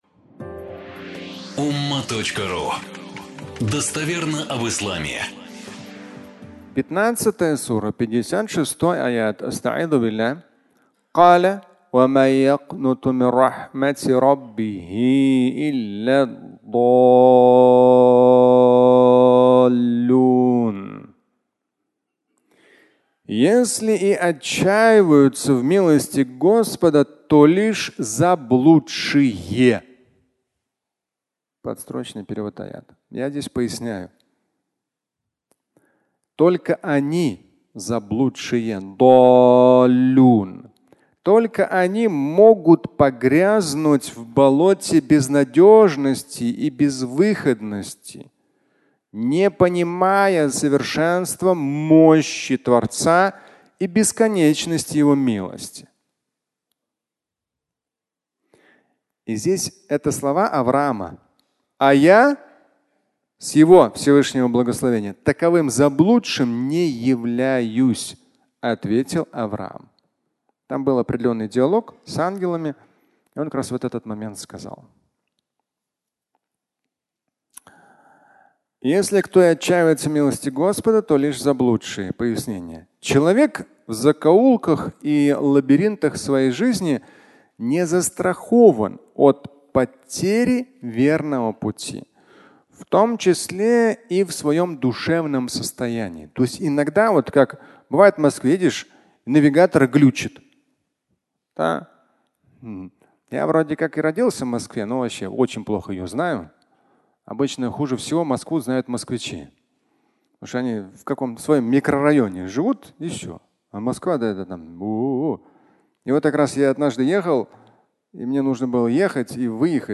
Закоулки жизни (аудиолекция)
Фрагмент пятничной лекции